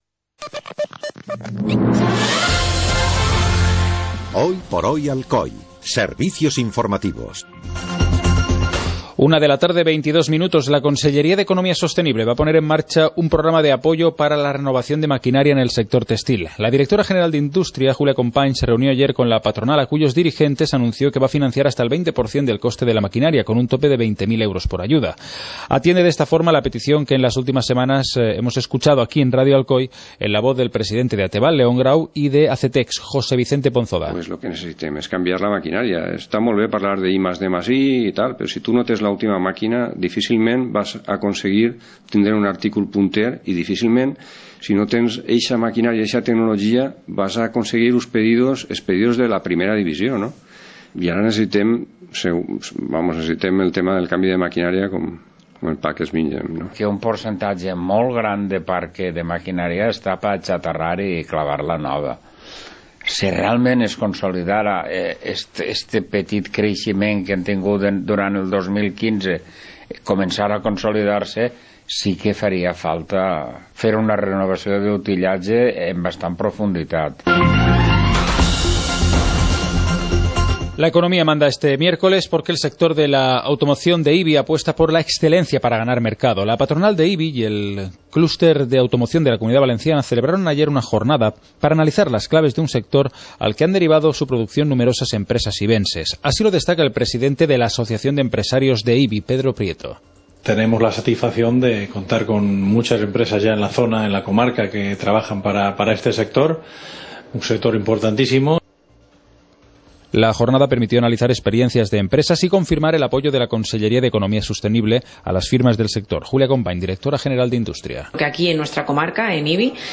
Informativo comarcal - miércoles, 17 de febrero de 2016